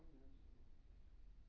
room_dishwasher-15-33.wav